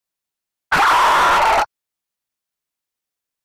Alien Screech Scream 4 - Monster Dinosaur